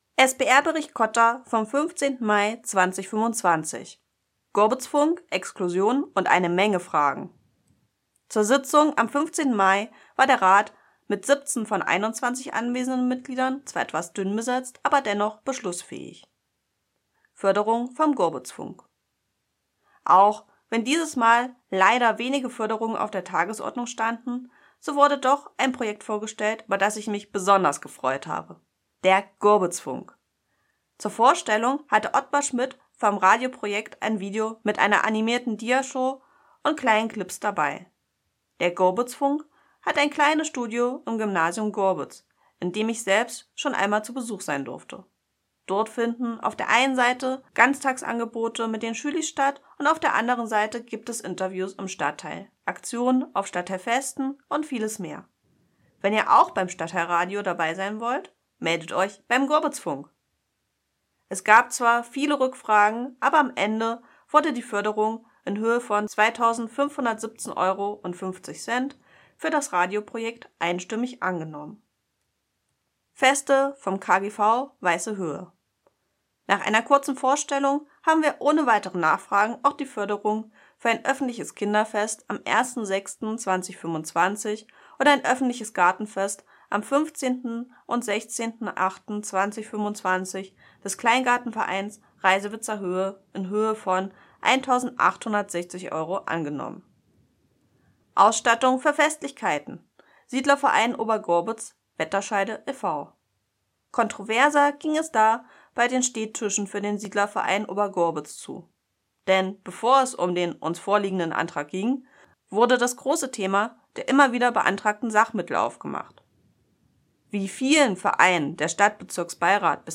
Unsere Stadtbezirksbeirätin Stephanie Henkel berichtet von der